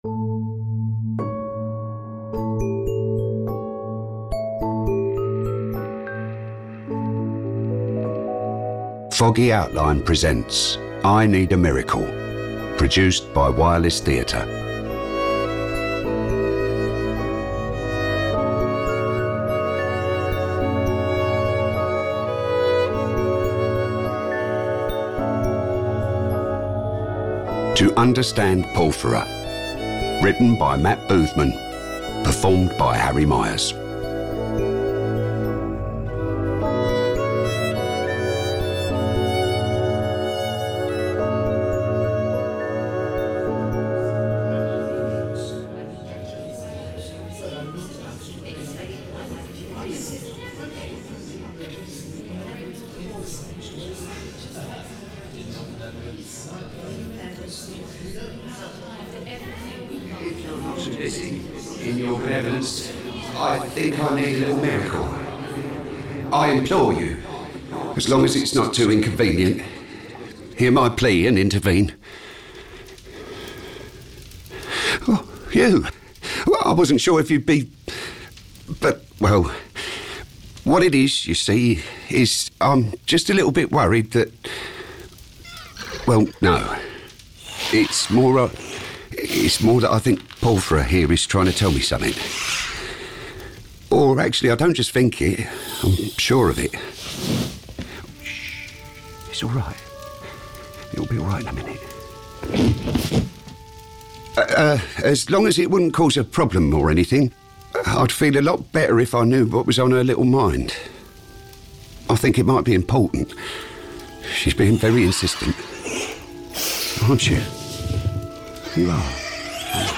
With additional voices from the season 1 cast